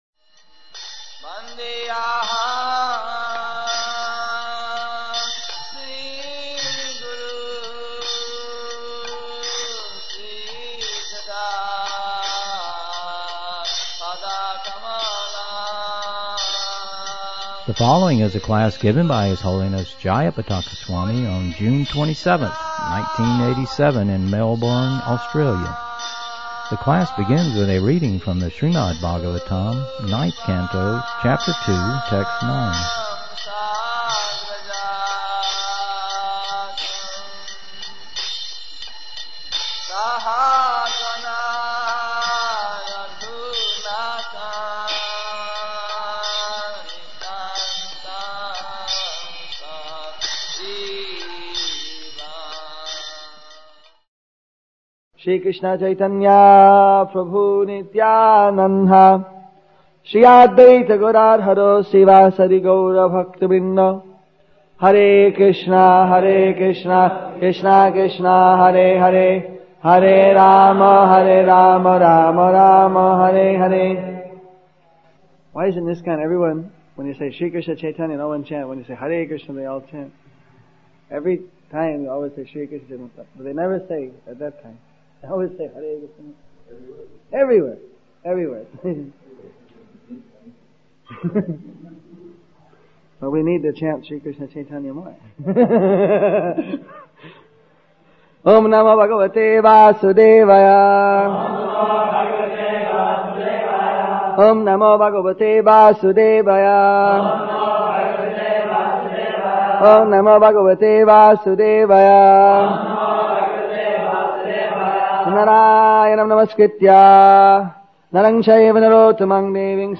Lectures